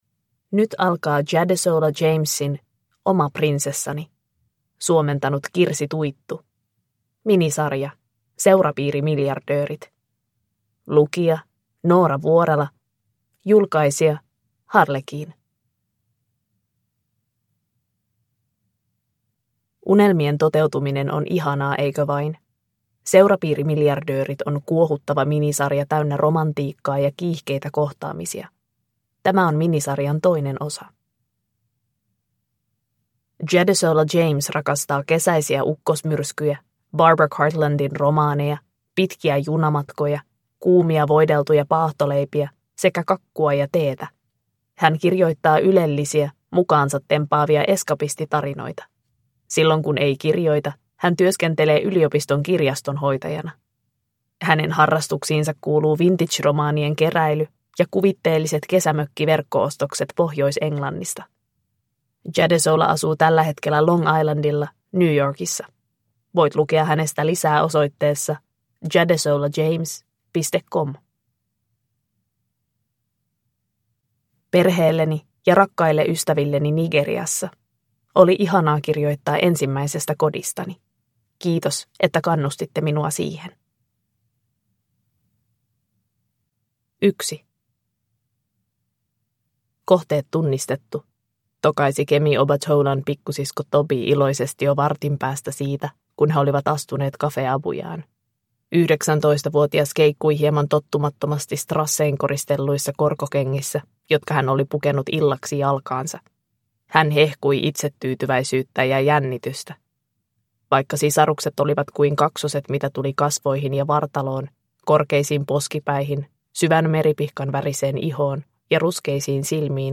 Oma prinsessani (ljudbok) av Jadesola James